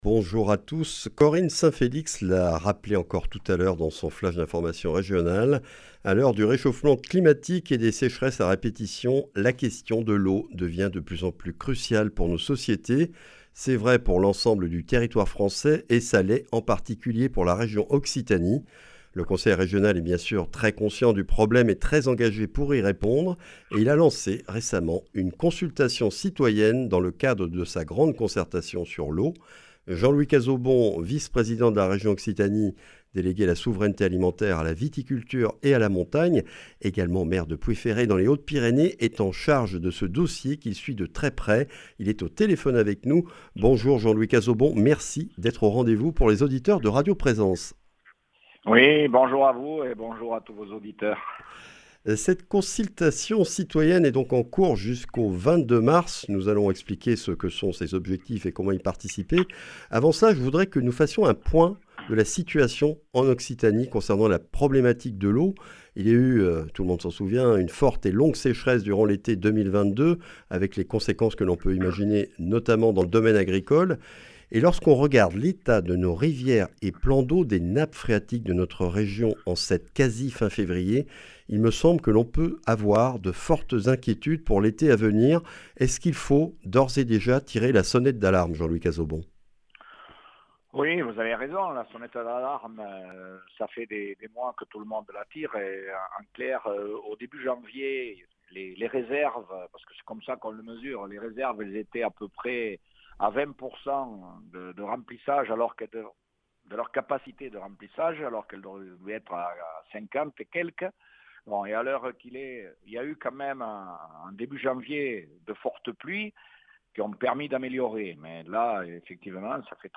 Des précisions avec Jean-Louis Cazaubon, vice-président du Conseil régional.
Présence Matin L’invité